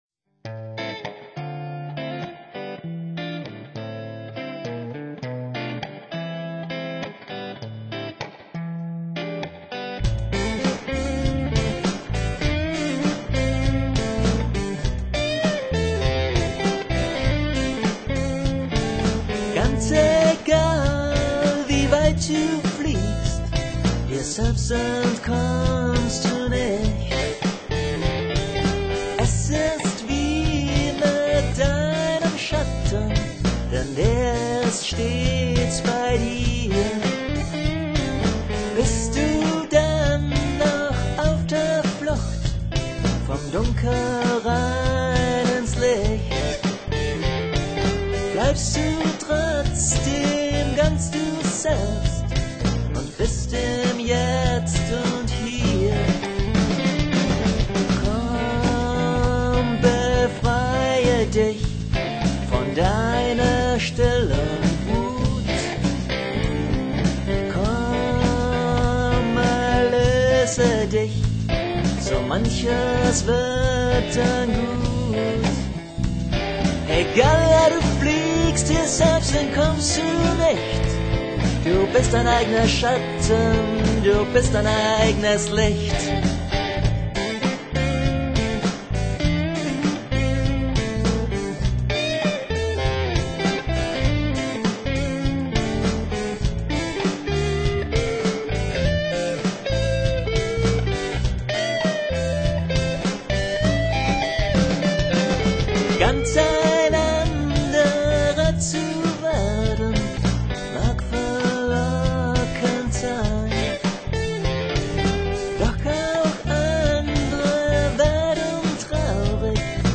vocals, e-guitars, bass, drum-arrangement